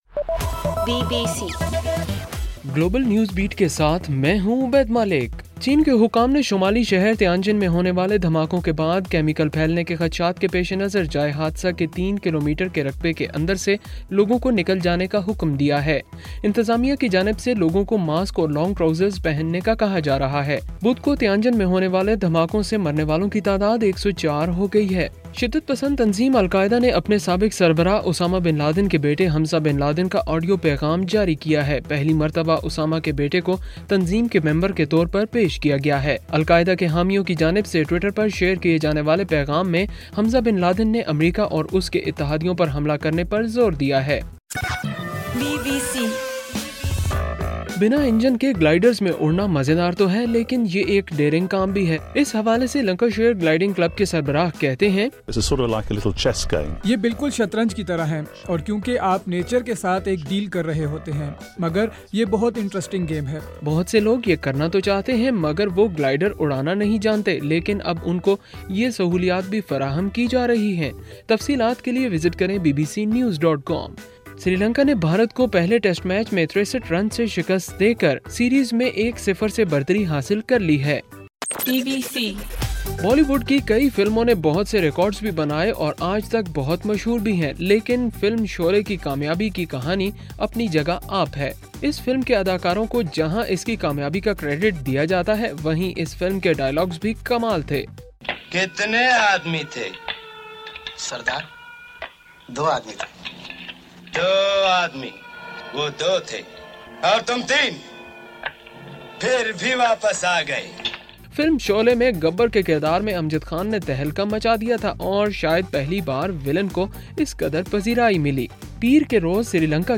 اگست 15: رات 11 بجے کا گلوبل نیوز بیٹ بُلیٹن